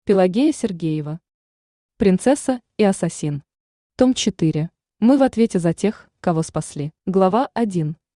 Аудиокнига Принцесса и ассасин. Том 4 | Библиотека аудиокниг
Читает аудиокнигу Авточтец ЛитРес